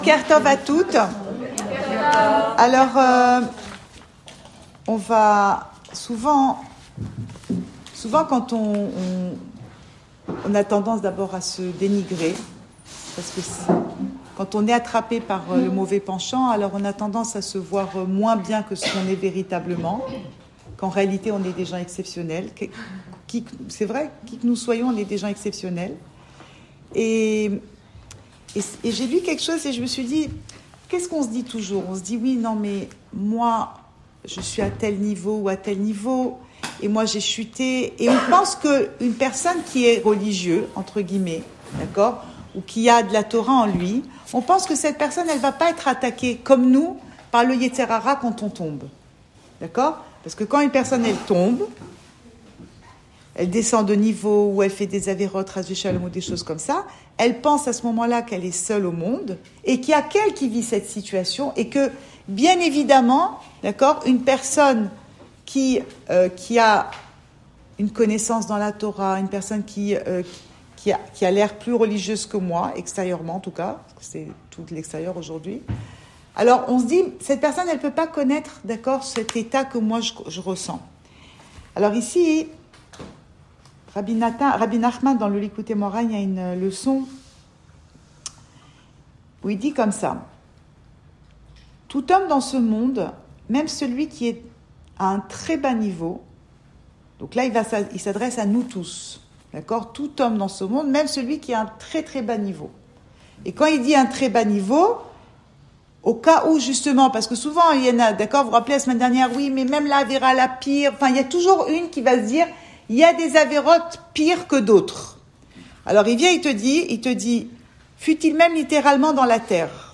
Cours audio Emouna Le coin des femmes Pensée Breslev - 10 juin 2020 11 juin 2020 La valeur d’une parole. Enregistré à Tel Aviv